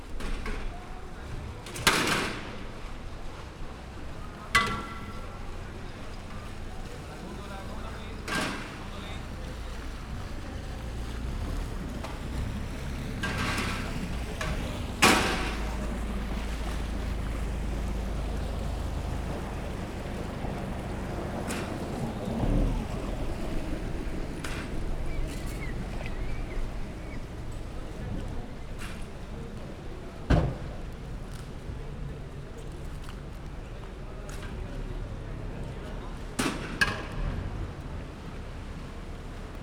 balramunkalatok_jobbrolmotorcsonak_velence_sds00.39.WAV